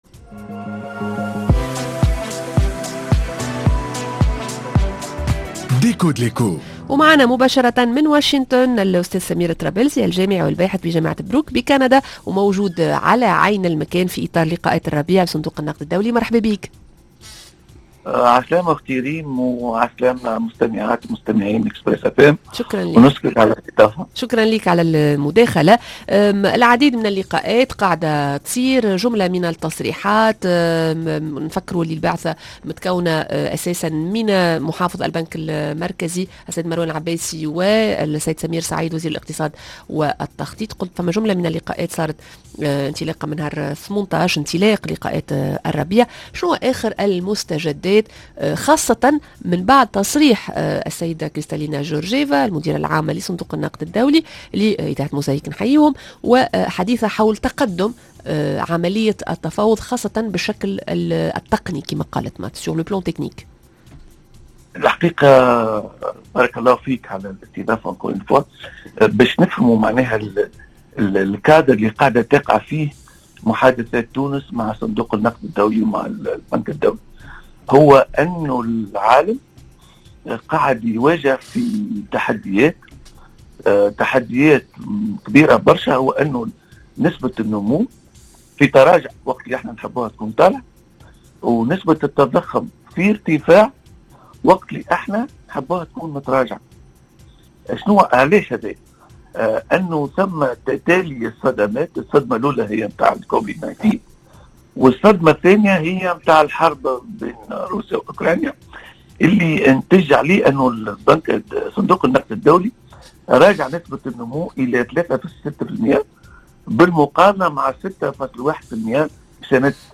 مباشرة من واشنطن- متابعة للقاءات الربيع لصندوق النقد الدولي